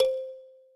kalimba_c.ogg